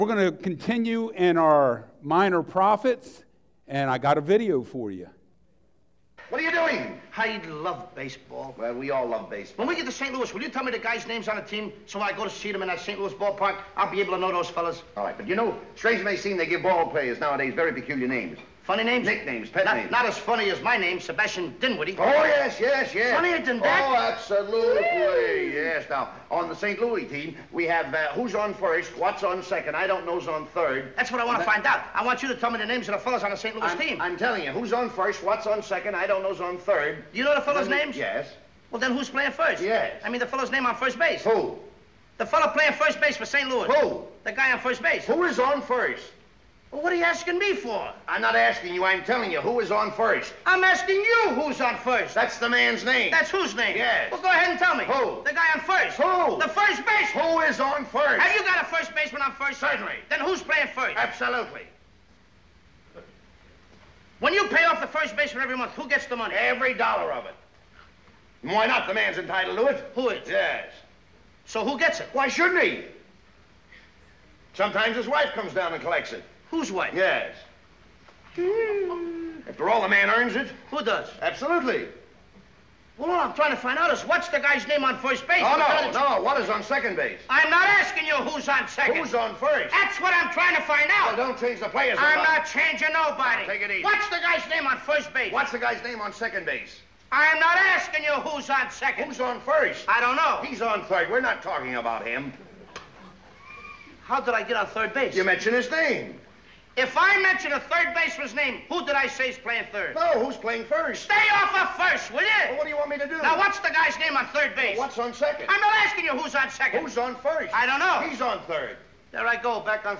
Study of Old Testament Prophets Service Type: Sunday Mornings A Study of Old Testament Prophets